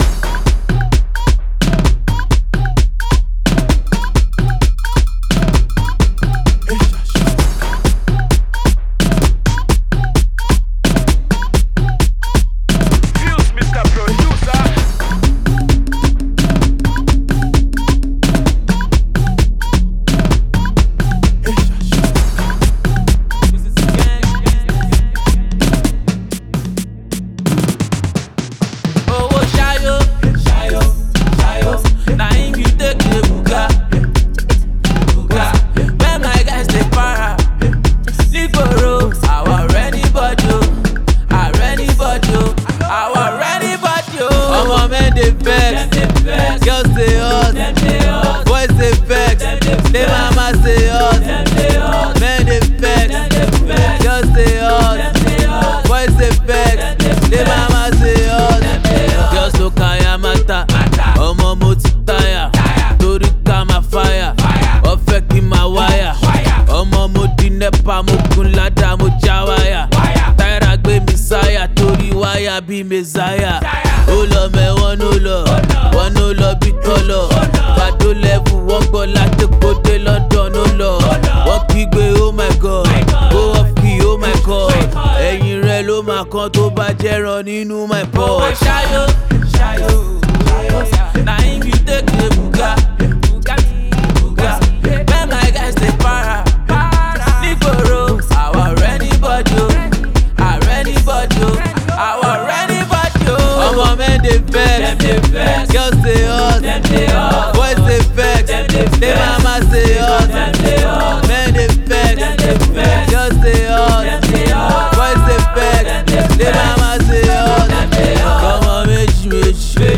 rapper
solo offering